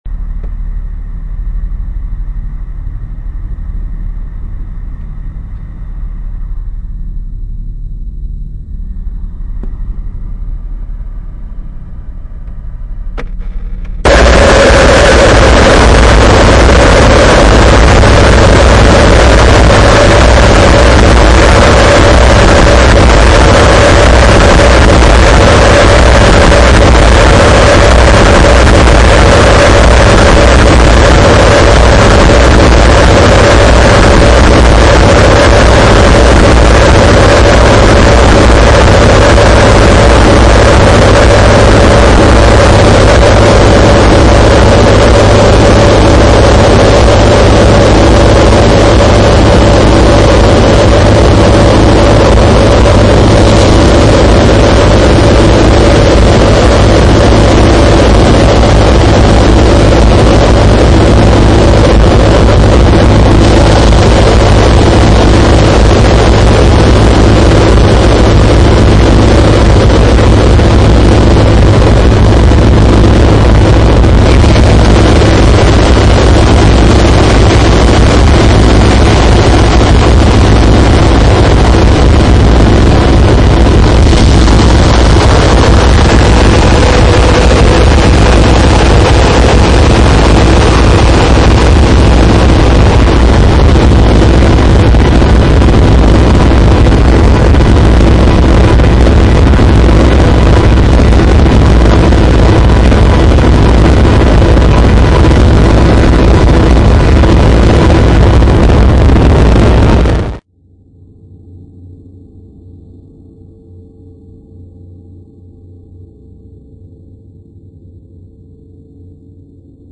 wall noise
dark ambient/sinister guitar drone